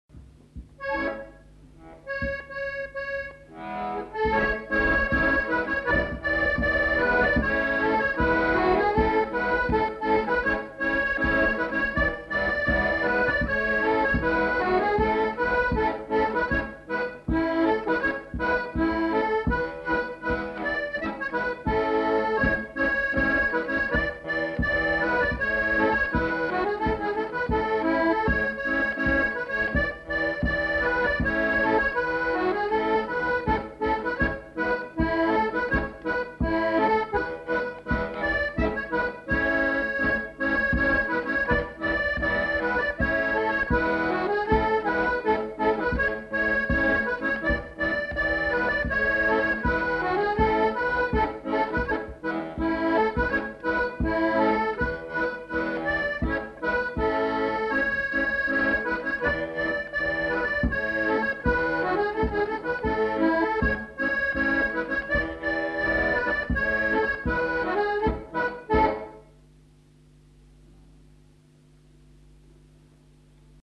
Scottish